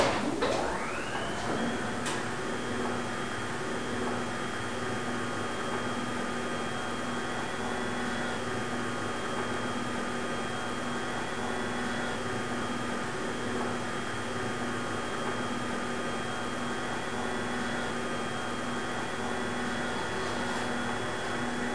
00035_Sound_lift_move.mp3